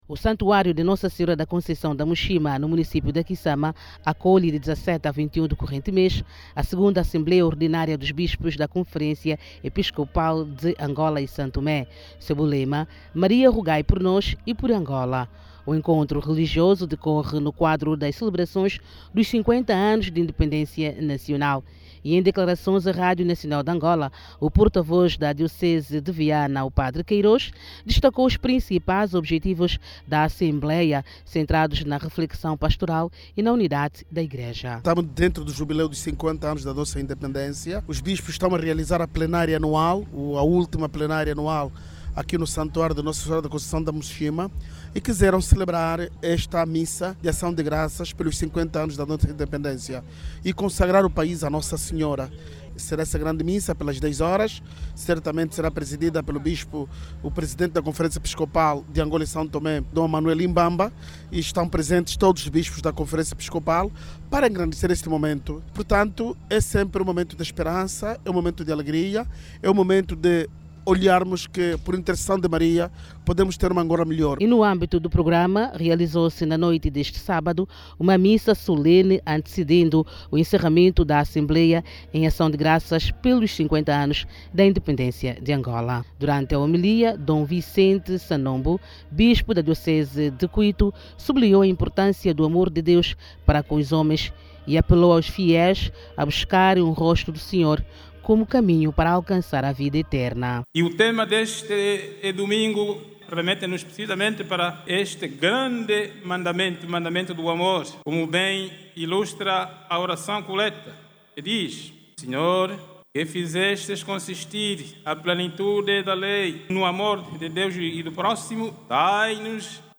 O Amor, é o tema que vai centralizar a celebração da Missa de Acção de Graças pelos Cinquenta Anos de Independência Nacional, que teve lugar hoje, domingo, 21, na Vila da Muxima, município da Quissama. A celebração, vai marcar o encerramento da Segunda Assembleia ordinária dos Bispos da Conferência Episcopal de Angola e São Tomé e Príncipe. Saiba mais dados no áudio abaixo com a repórter